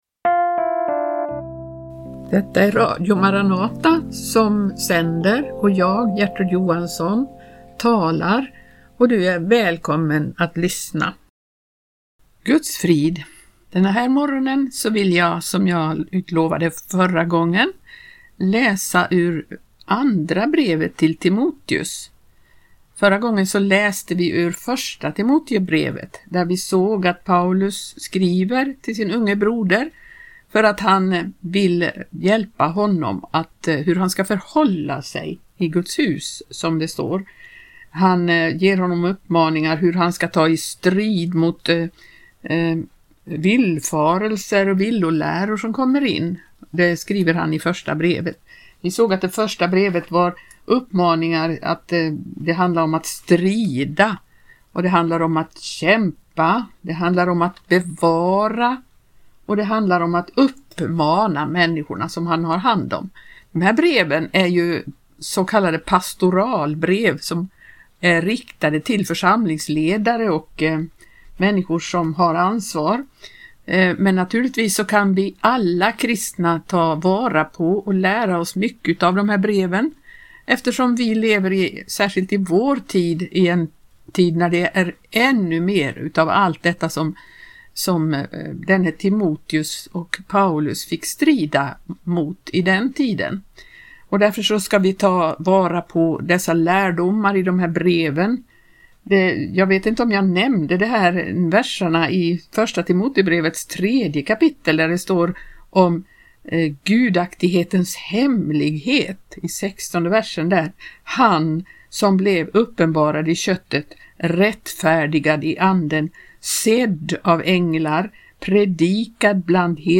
läser ur 2 Timoteusbrevet i Radio Maranata